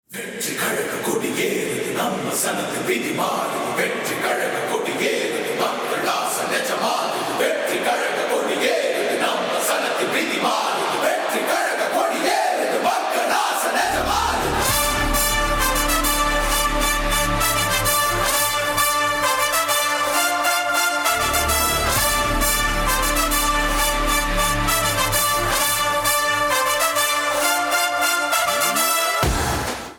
• Orchestral intro with strong rhythm
• Punchy and attention-grabbing from the first second